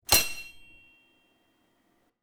SwordSoundPack
SWORD_01.wav